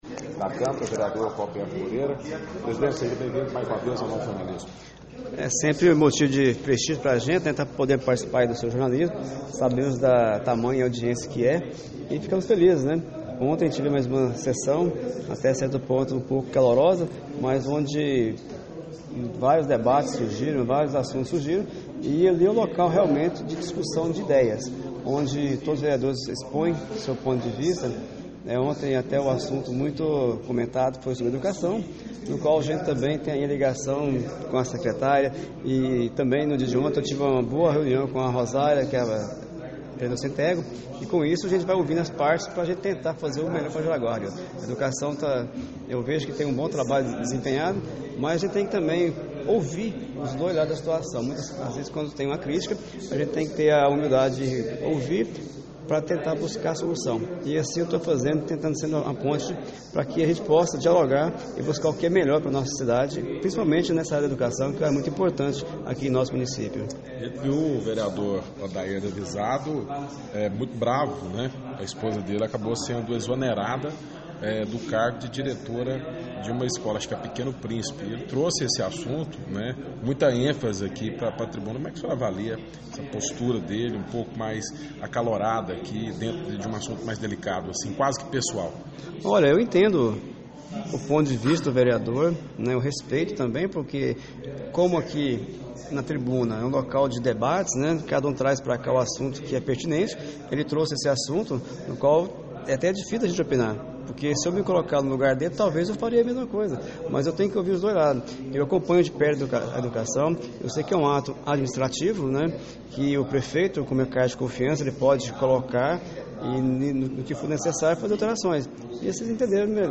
Casos de perseguição contra funcionários são comuns na administração pública, o que acontece em vários órgãos da administração pública, episódios que o presidente da Câmara Roberto Moreira (PP) procura amenizar por meio de diálogo entre vereadores e gestores, conforme disse em entrevista abaixo.